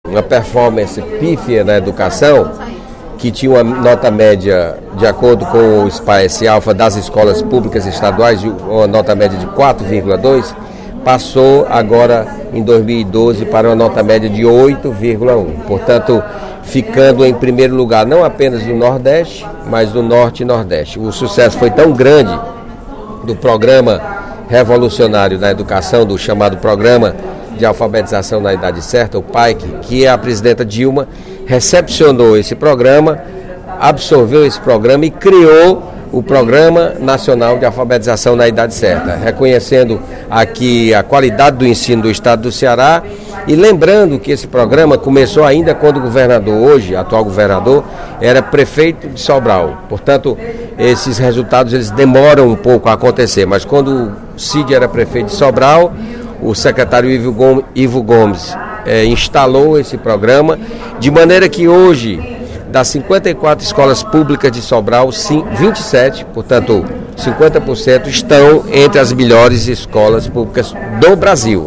O deputado José Sarto (PSB), líder do Governo na Assembleia Legislativa, fez pronunciamento nesta quinta-feira (07/02) para destacar as ações do Executivo Estadual na área de educação pública.